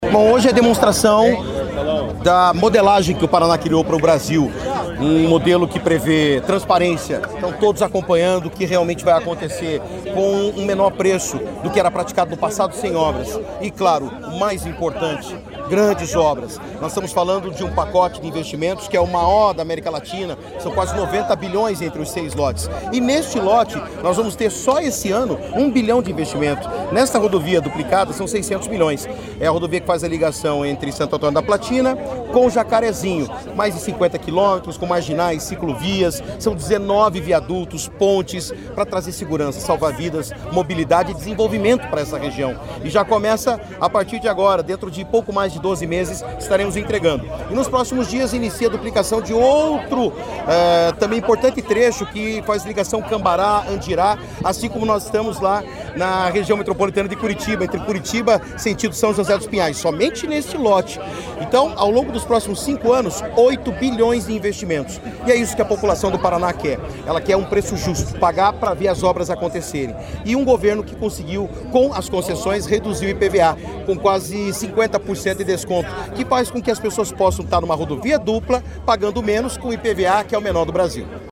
Sonora do secretário de Infraestrutura e Logística, Sandro Alex, sobre a duplicação da BR-153 entre Jacarezinho e Santo Antônio da Platina